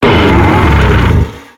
Cri de Tranchodon dans Pokémon X et Y.